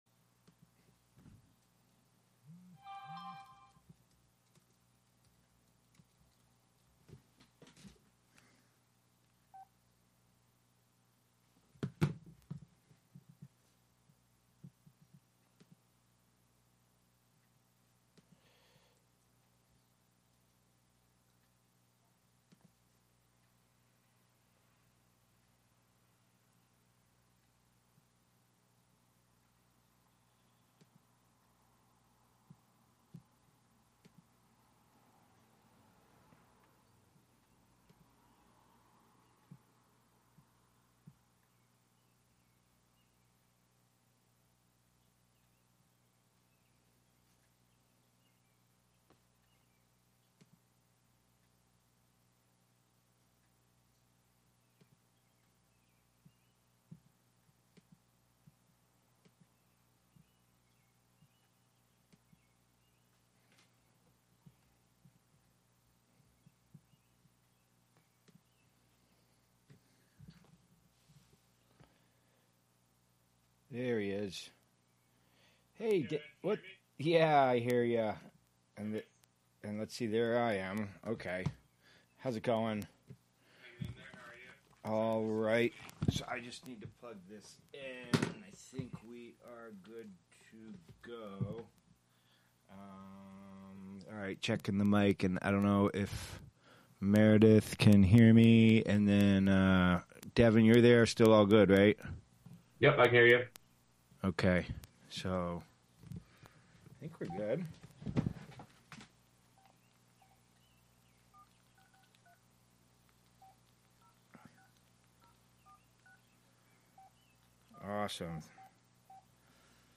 Prime Jive: Monday Afternoon Show- Live from Housatonic, MA (Audio)
broadcasts live with music, call-ins, news, announcements, and interviews